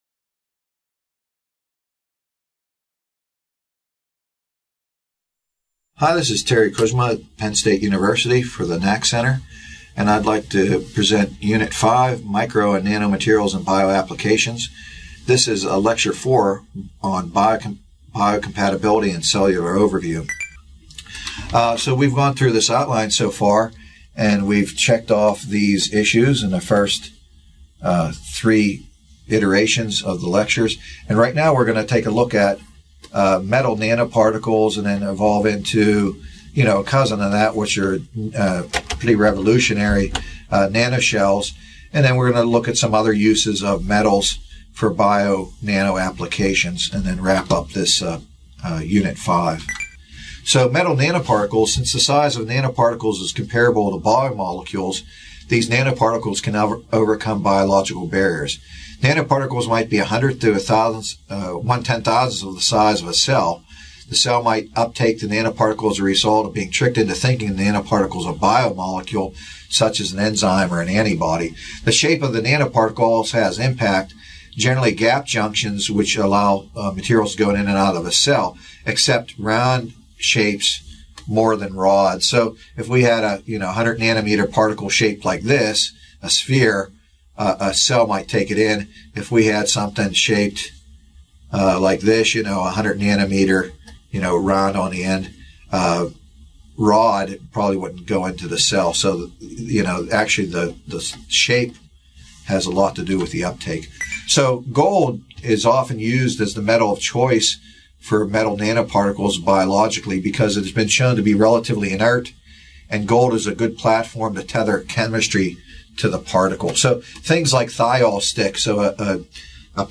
This video, provided by the Nanotechnology Applications and Career Knowledge Support (NACK) Center at Pennsylvania State University, is part four of a four-part lecture on the interactions between biological entities and products crafted on a nanoscale, with a focus on nanoparticles for drug delivery.